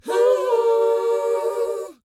WHOA F BD.wav